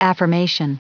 Prononciation du mot affirmation en anglais (fichier audio)
Prononciation du mot : affirmation